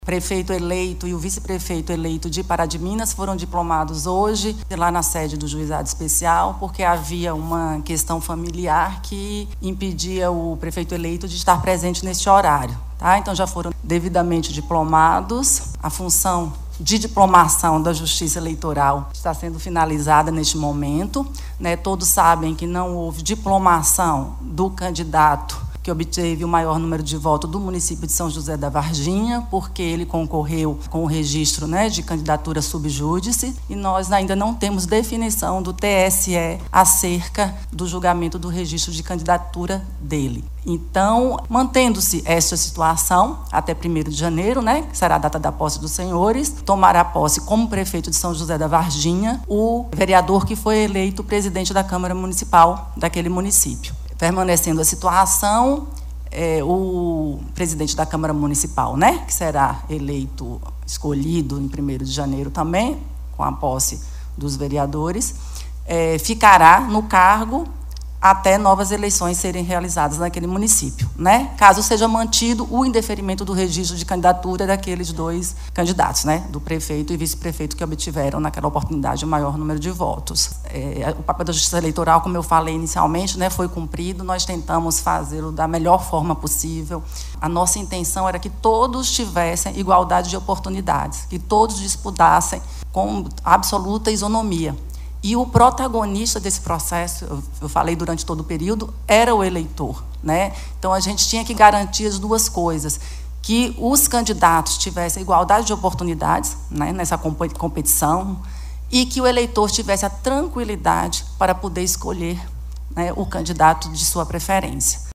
A diplomação dos eleitos ocorreu na noite desta sexta-feira (13), na Câmara Municipal de Pará de Minas, em solenidade presidida pela juíza Gabriela Andrade de Alencar Ramos.
A juíza também esclareceu o motivo pelo qual o prefeito e vice-prefeito que obtiveram maior número de votos em São José da Varginha não foram diplomados, Adianta que caso a situação continue indefinida até 1º de janeiro de 2025, o vereador que for escolhido presidente da Câmara Municipal assumirá o cargo de prefeito daquela cidade, até que a situação seja resolvida ou realizadas novas eleições: